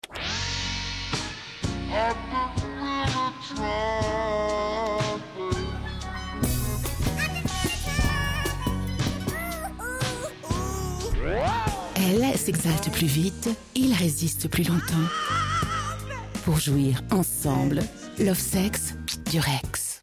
Stimmart: Alt, warm, klare, erzählerisch, reif, überzeugend, freundlich, facettenreich, markant.
Sprechprobe: Industrie (Muttersprache):